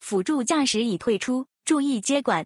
audio_disengage.wav